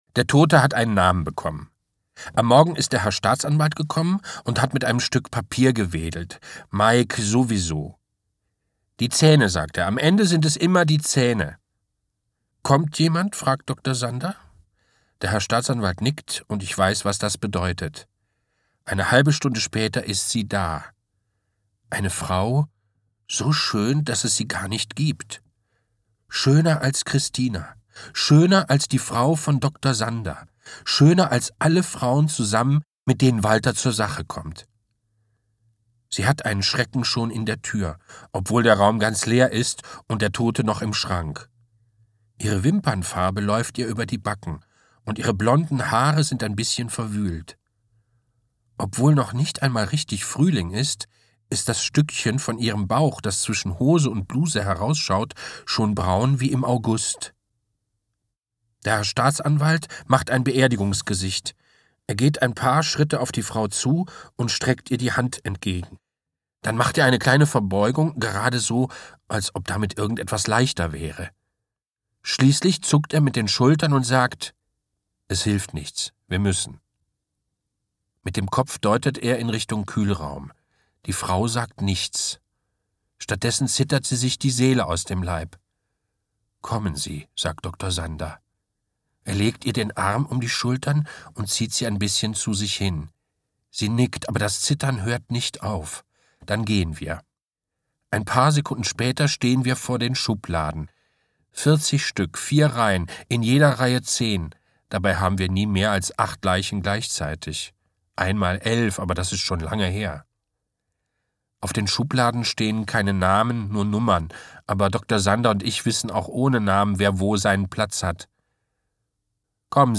Jürgen Tarrach (Sprecher)
Tarrach liest die Ich-Erzählung aus einer so tiefen und treuherzigen Seele heraus, dass einem ganz warm wird ums Herz."